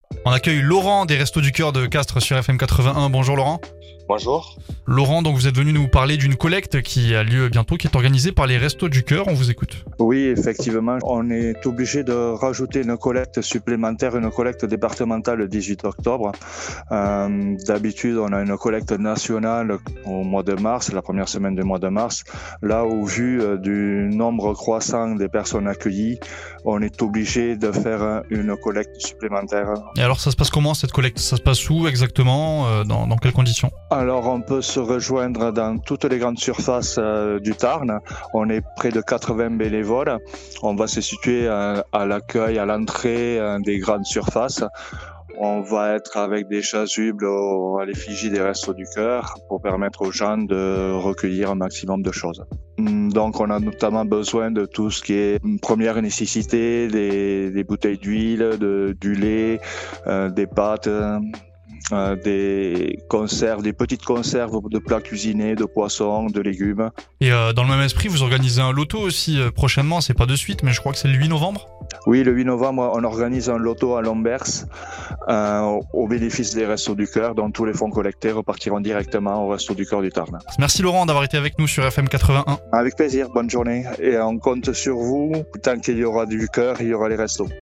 LA VIE LOCALE DU TARN